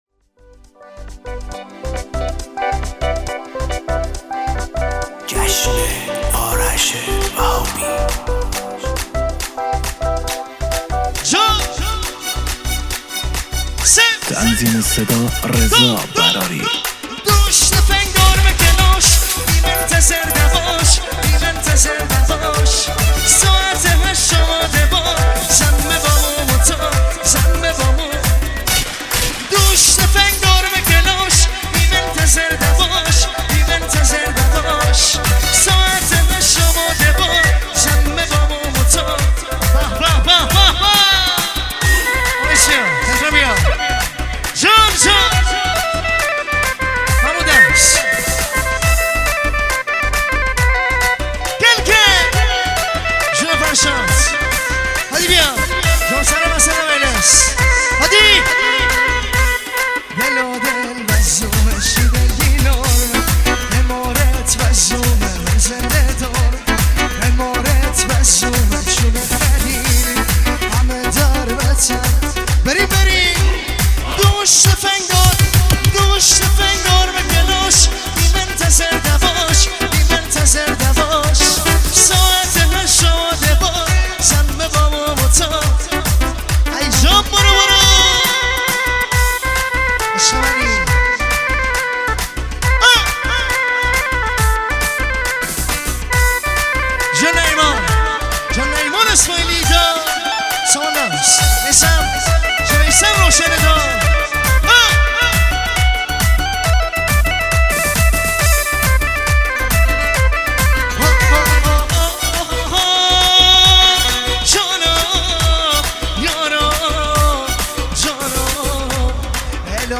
شاد با ارگ